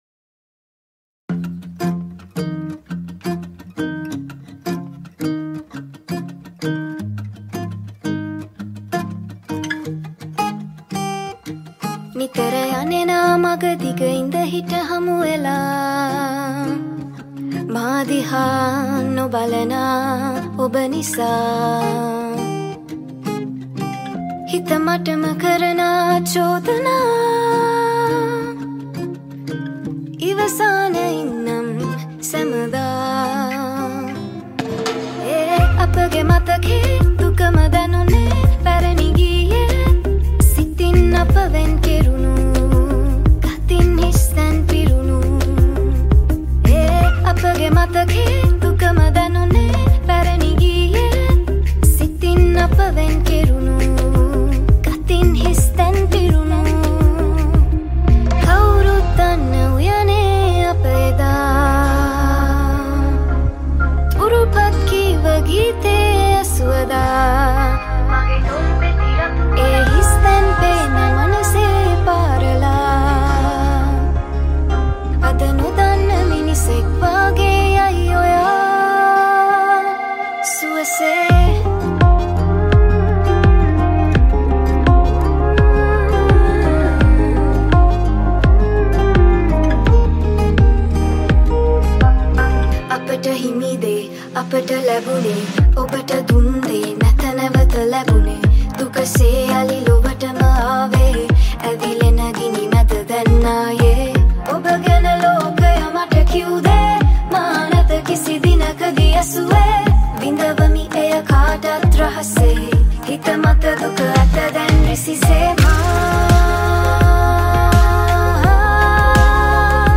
High quality Sri Lankan remix MP3 (3.6).
remix